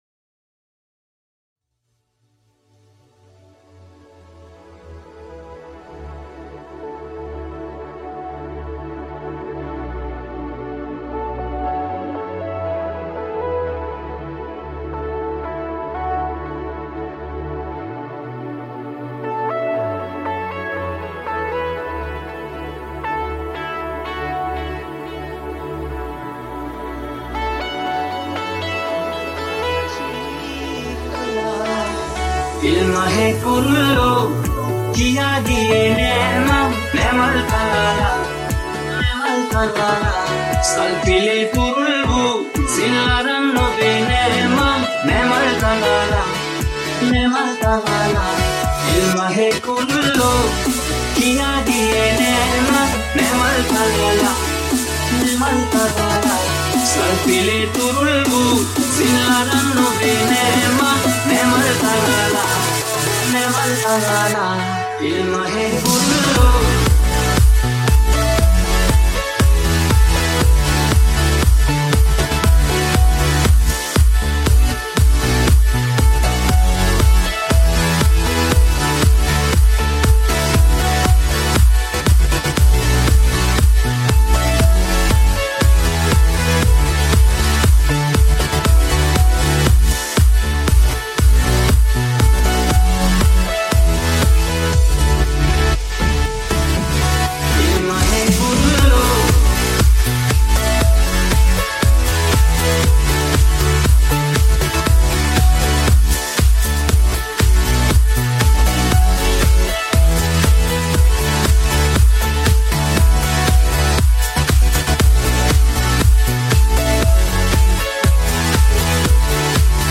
EDM Remake